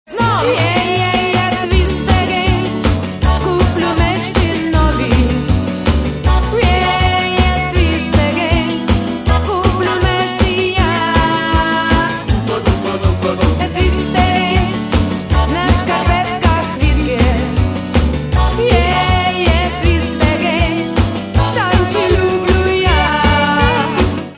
Каталог -> Поп (Легкая) -> Юмор
Легкая и энергичная музыка, шутка в каждой строке.